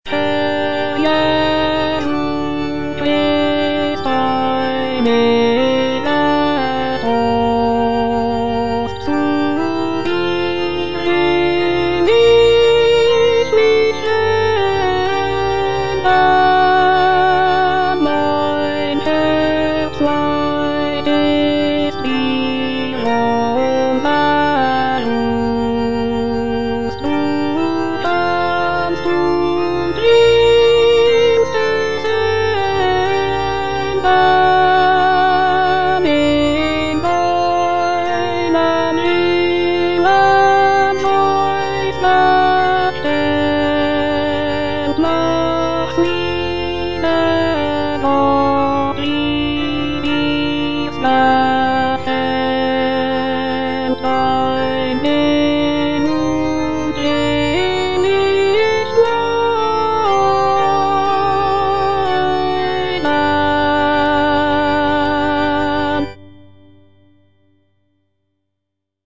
Cantata
Alto (Voice with metronome) Ads stop